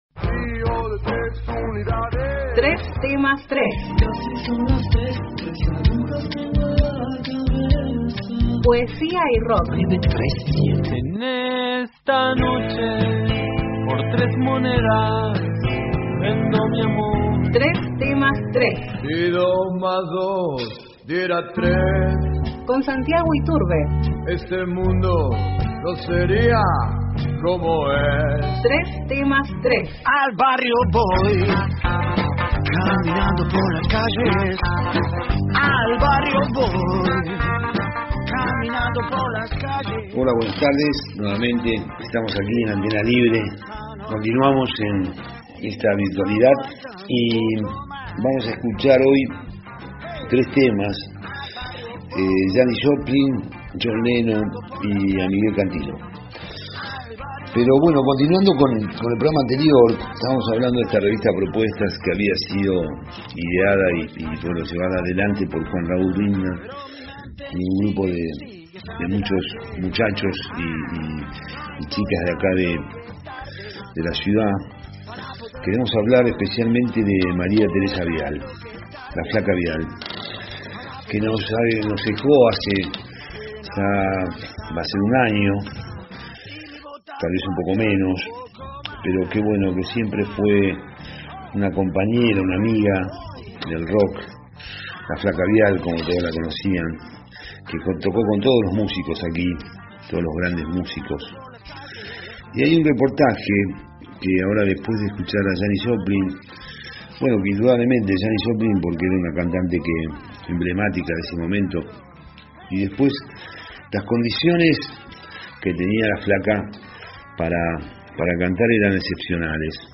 Columna de rock y poesía